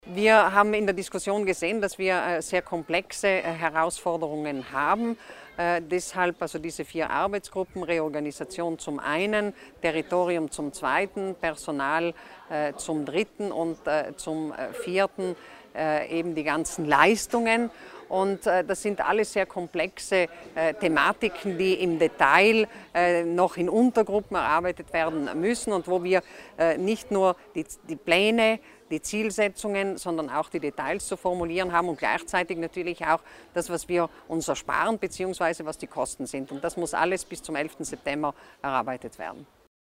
Landesrätin Martha Stocker zu den Ergebnissen der Klausurtagung des Gesundheitswesens